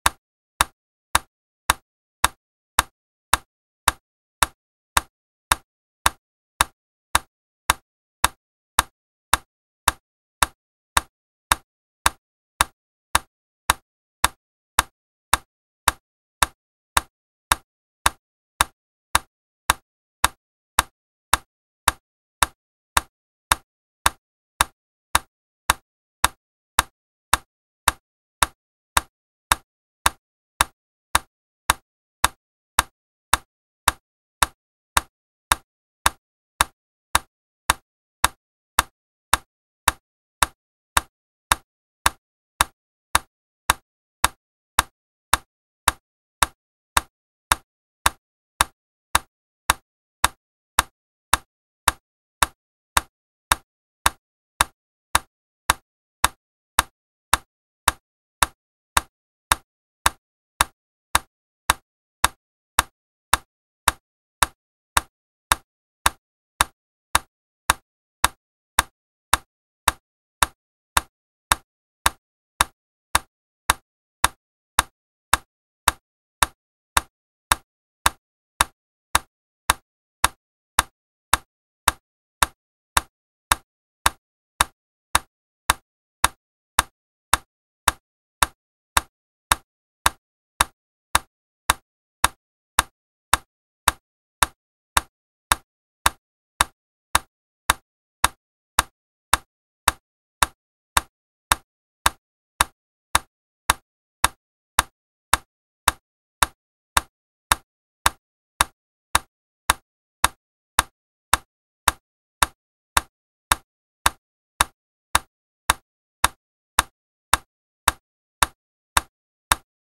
110-BPM-Metronome.mp3